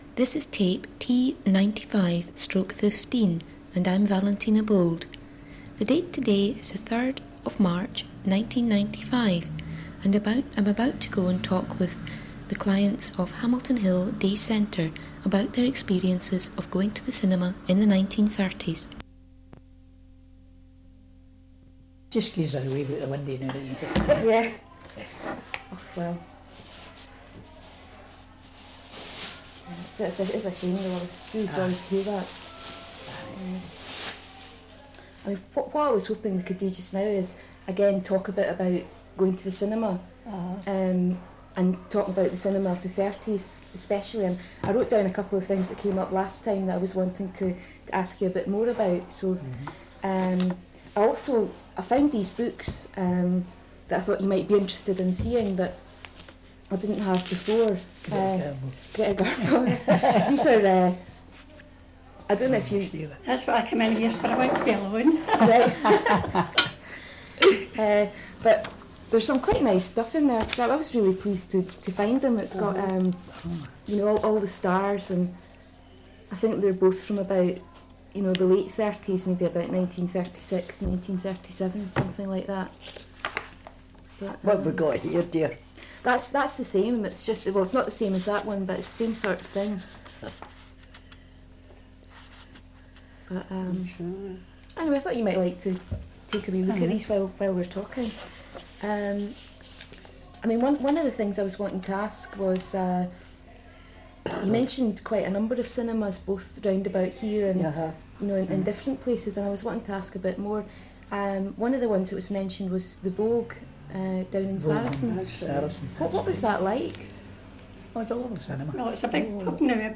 Sound Quality: Fair